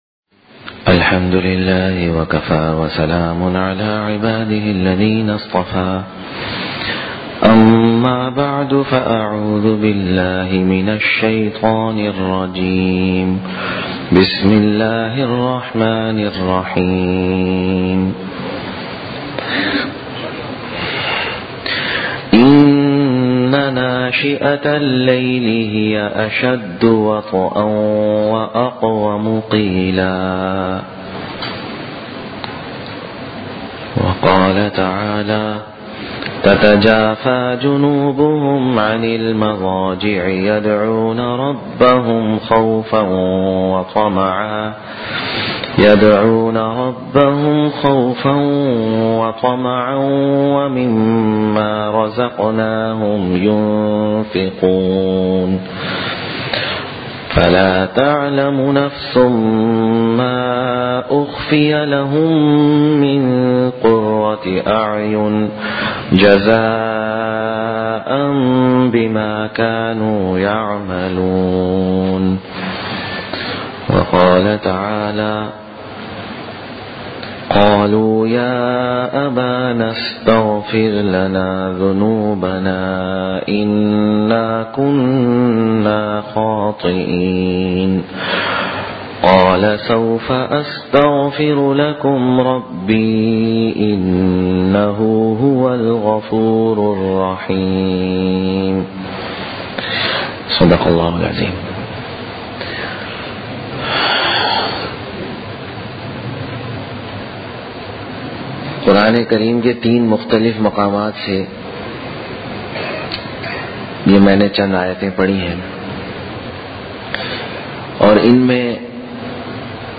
Sunday Sermons (Bayans)